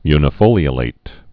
(ynĭ-fōlē-ə-lāt)